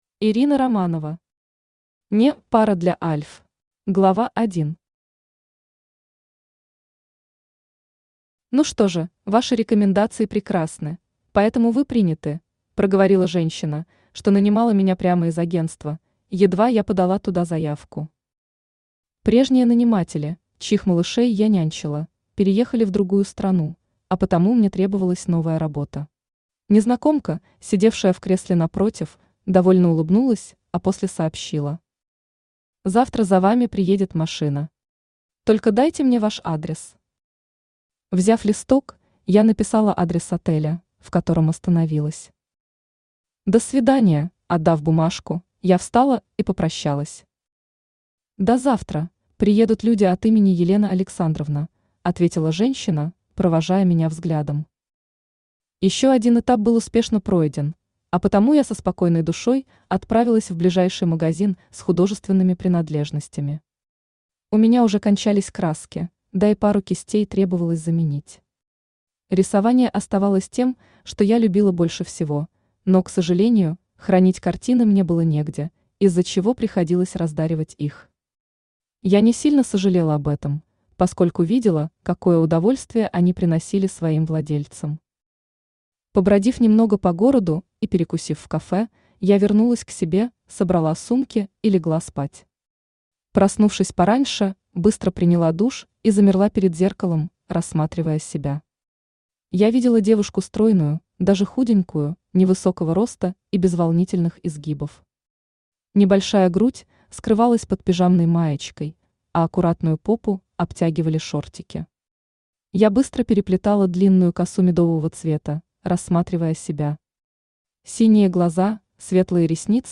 Аудиокнига (Не) пара для альф | Библиотека аудиокниг
Aудиокнига (Не) пара для альф Автор Ирина Романова Читает аудиокнигу Авточтец ЛитРес.